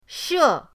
she4.mp3